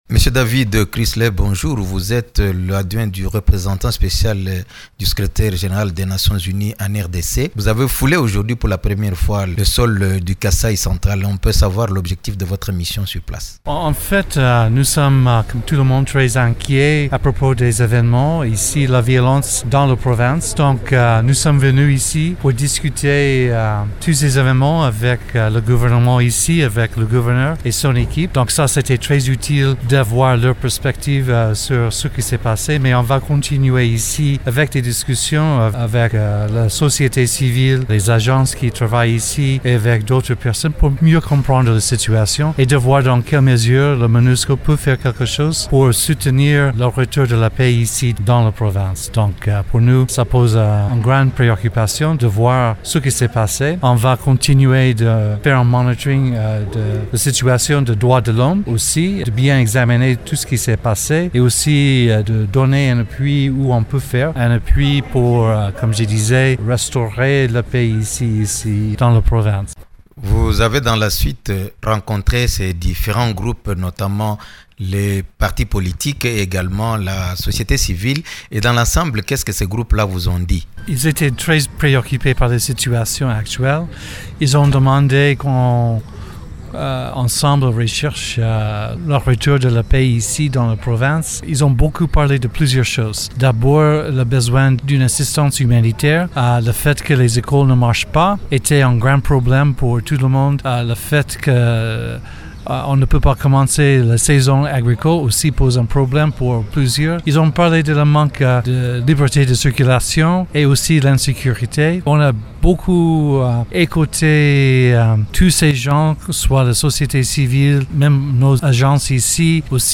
Invité de la rédaction ce mercredi, le représentant spécial adjoint du Secrétaire général des Nations unies en RDC, David Gressly, nous parle de la situation sur place.